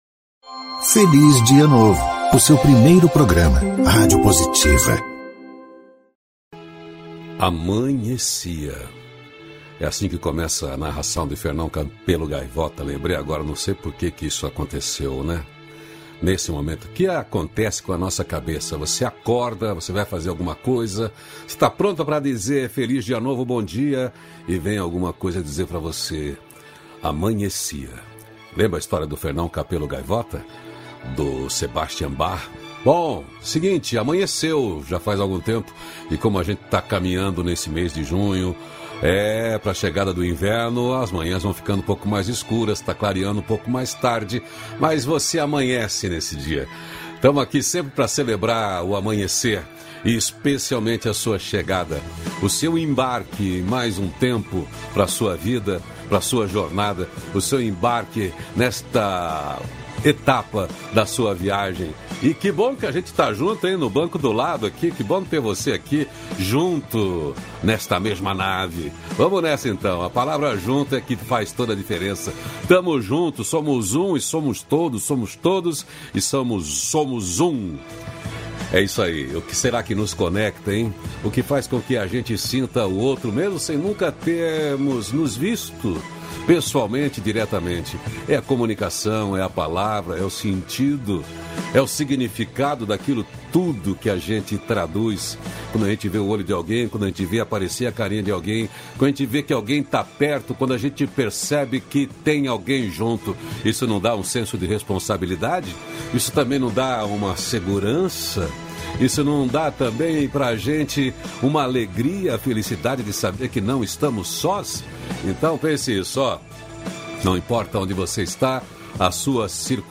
-476FelizDiaNovo-Entrevista.mp3